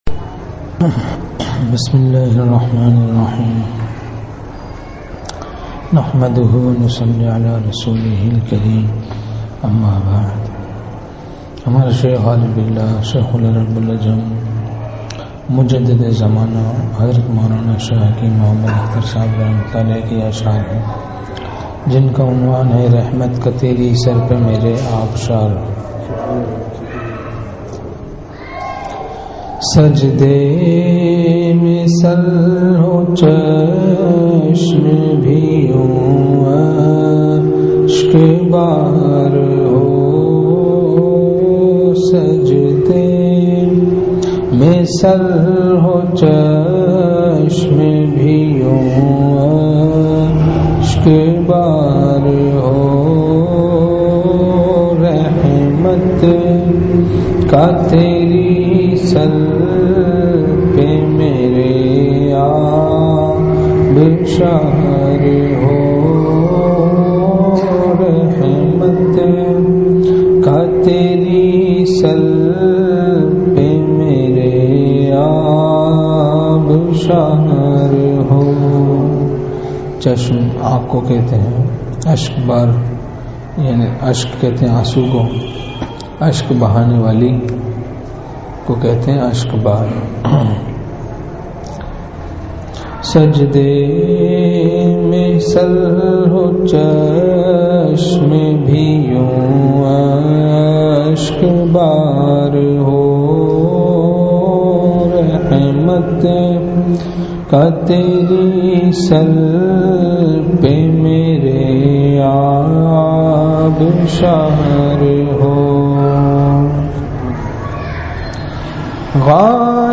مدرسہ اصحاب کہف گودھرا میں حضرت کا بیان